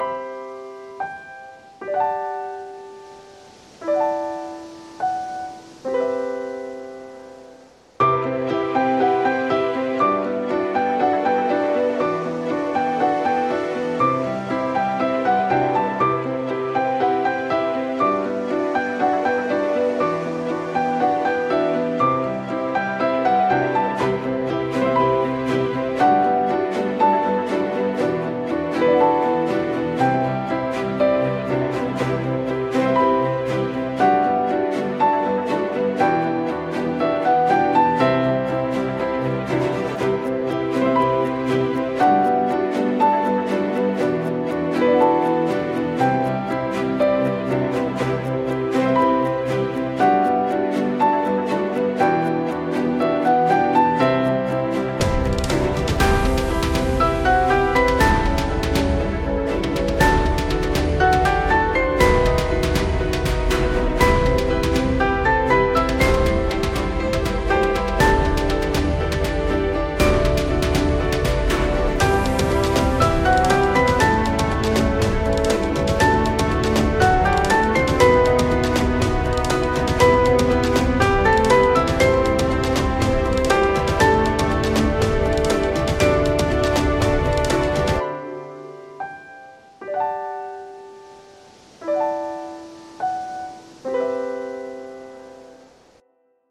Strings библиотеки под Контакт